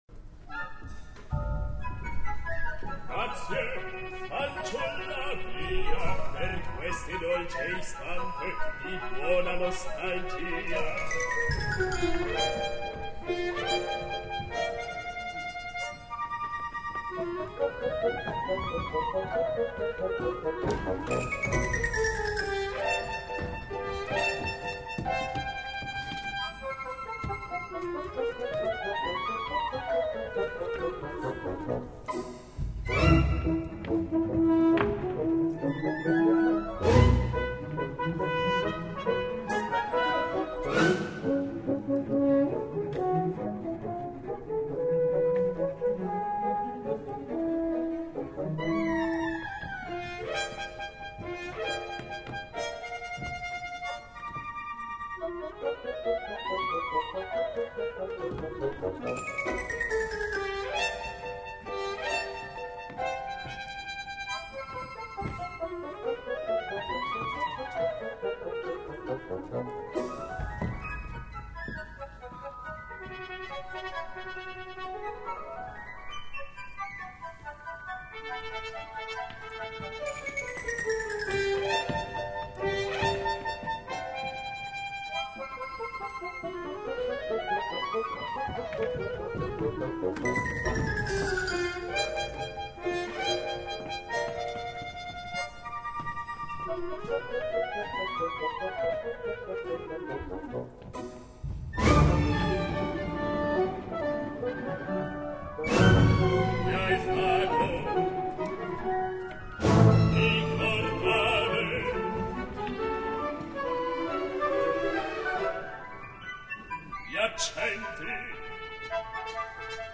{Verismo}